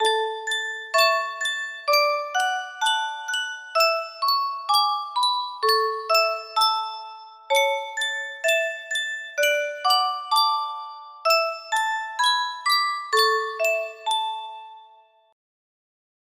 Yunsheng Spilledåse - I skoven skulle være gilde 5489 music box melody
Full range 60